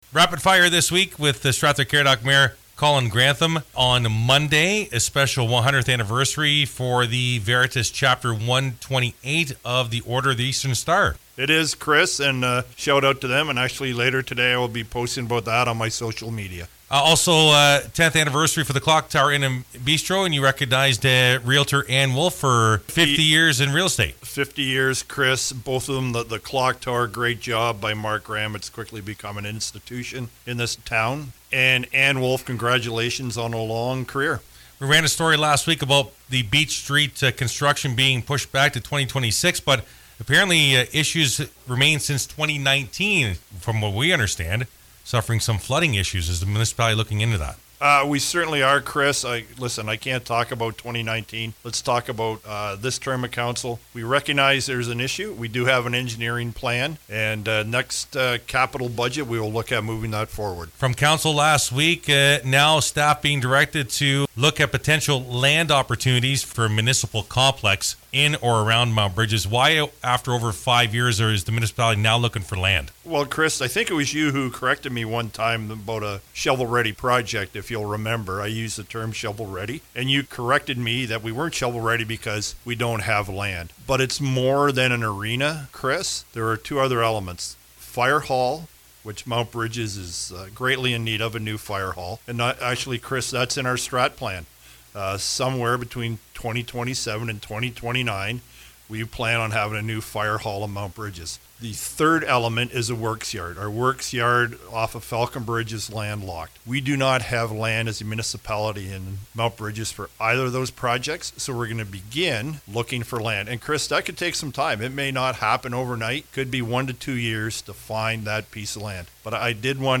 mayor-june-9-web-interview.mp3